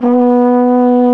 TENORHRN B 1.wav